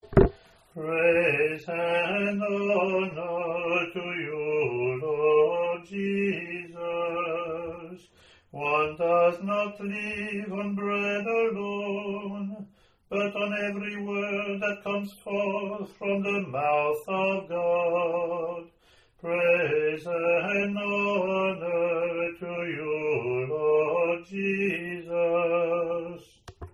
Gospel Acclamation
lt01-gospacc-eng-aae.mp3